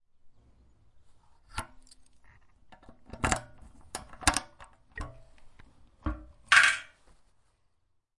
06 打开油箱